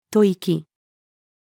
屠殺-female.mp3